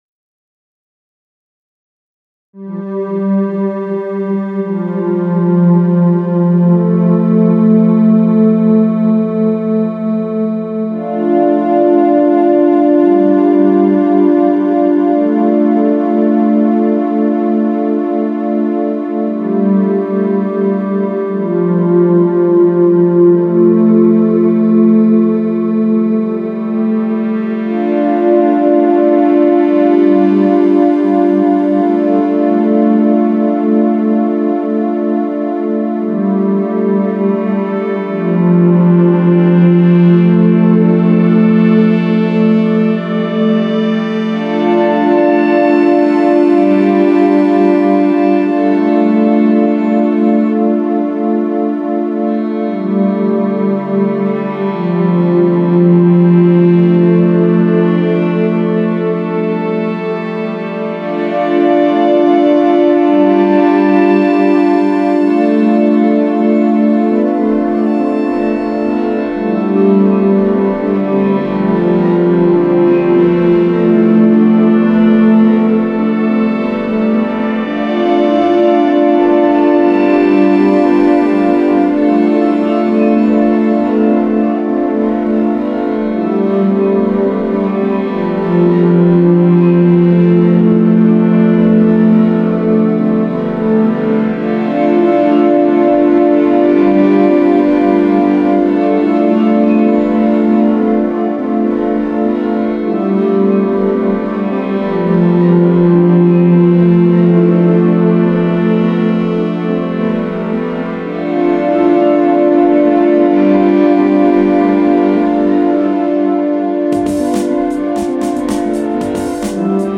electronic ambient music
ambient music